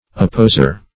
Search Result for " apposer" : The Collaborative International Dictionary of English v.0.48: Apposer \Ap*pos"er\, n. An examiner; one whose business is to put questions.